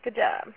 Seven prosodic variants of good job (au files):
creaky-elongated
creaky-elong.au